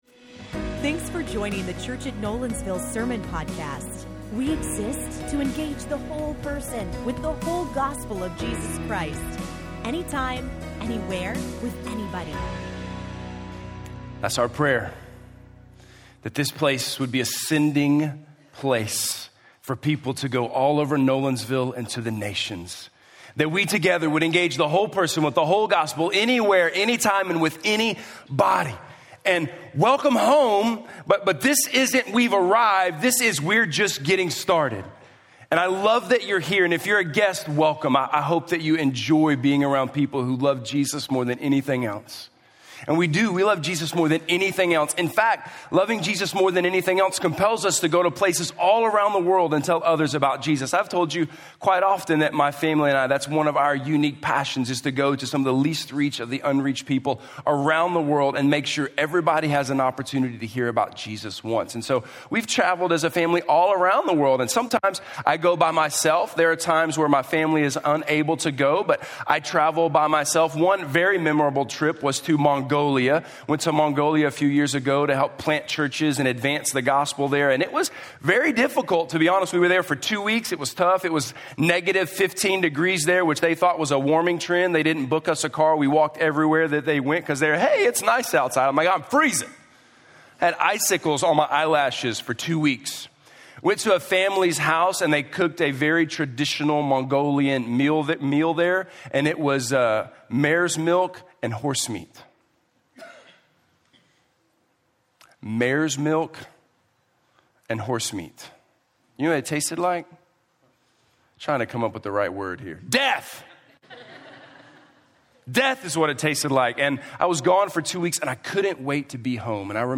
All Things New - Sermon - Nolensville